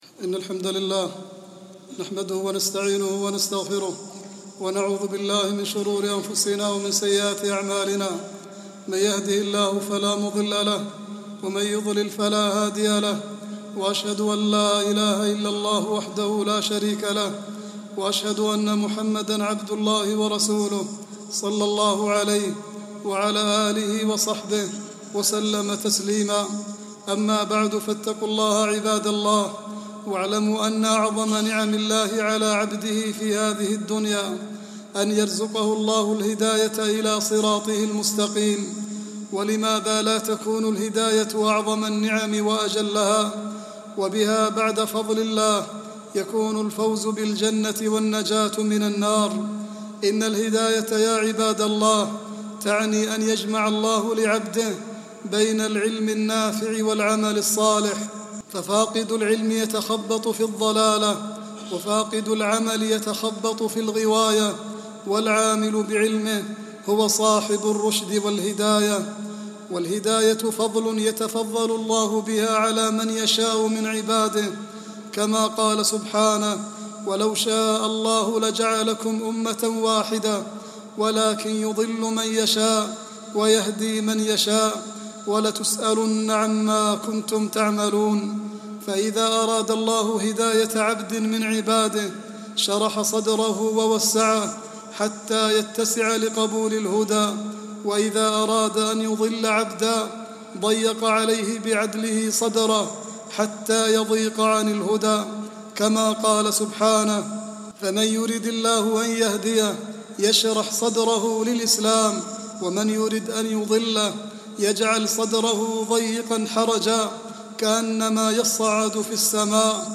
khutbah-10-7-38.mp3